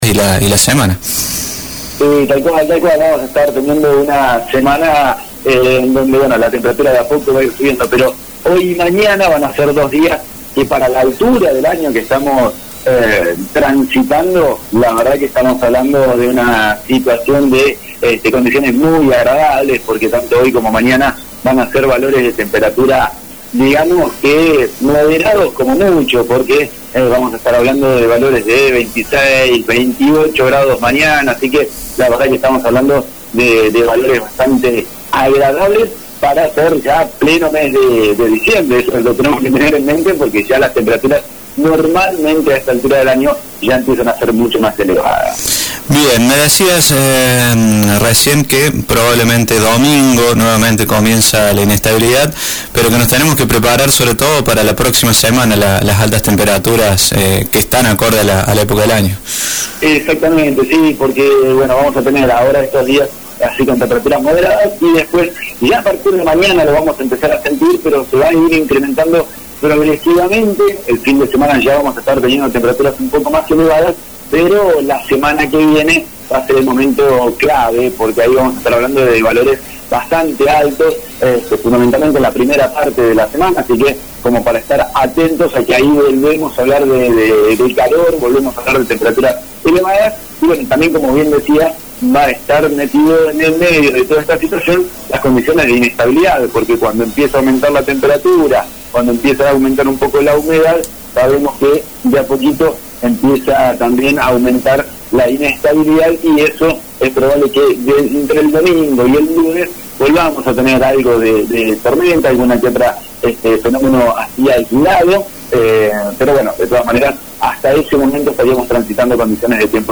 pronóstico del tiempo para la jornada de hoy.